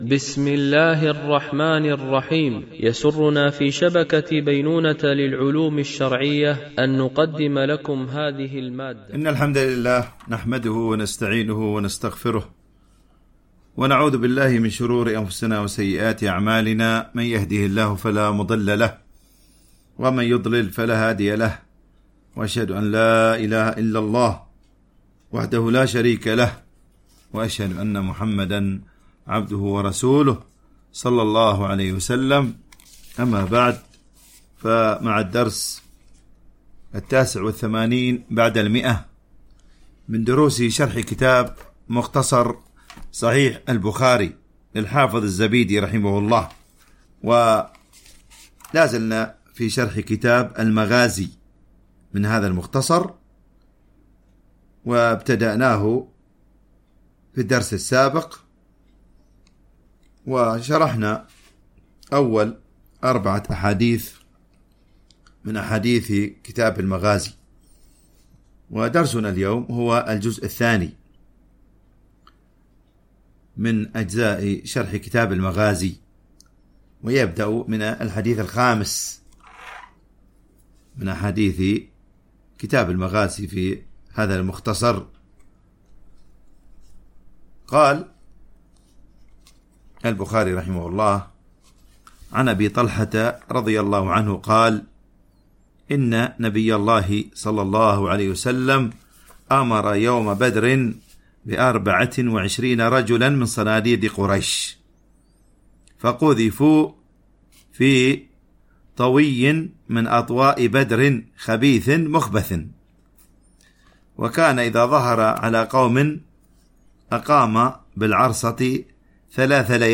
MP3 Mono 44kHz 64Kbps (VBR)